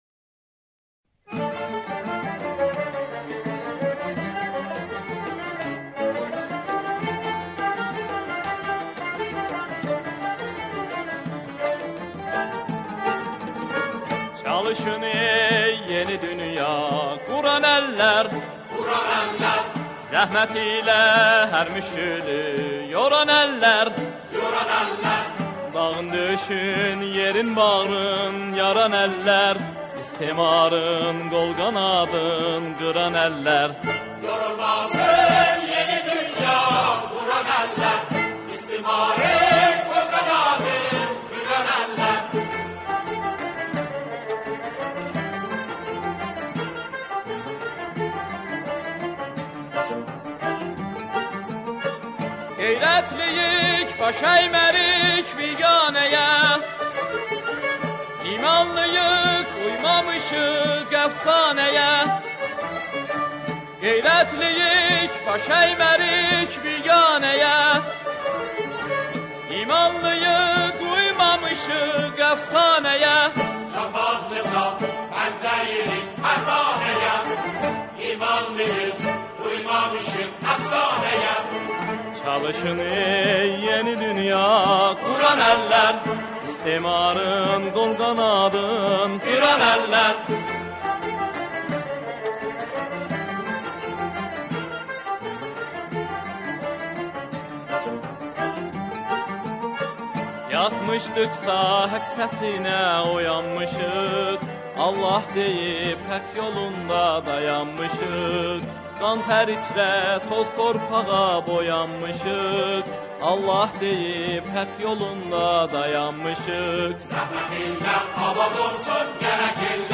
سرودهای انگیزشی
همخوانی شعری انگیزشی